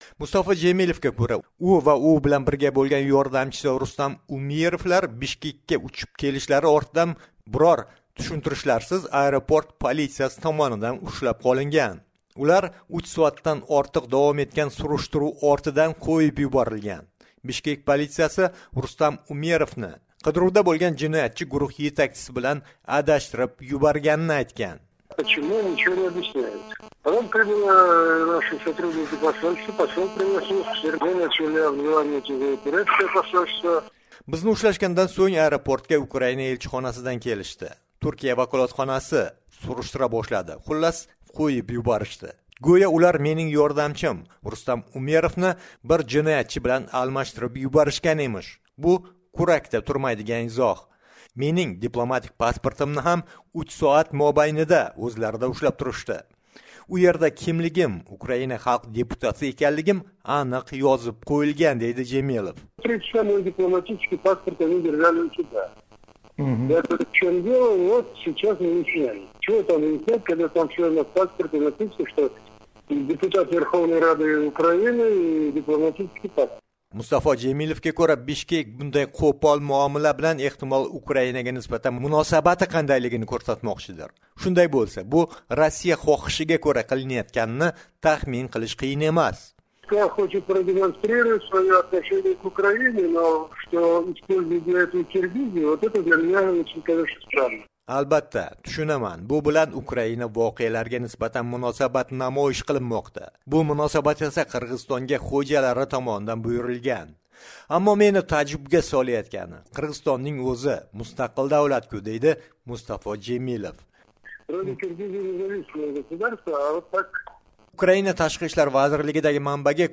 Xalqaro konferensiyada ishtirok etish uchun Qirg'izistonga borgan Qrim tatarlari rahbari Mustafo Jemilev Bishkek aeroportida so'roq qilingan. Ukraina va Turkiya vakolatxonalari aralashivu bilan Jemilev va uning yordamchisi Rustam Umerov qo'yib yuborilgan. "Amerika Ovozi" bilan suhbatda...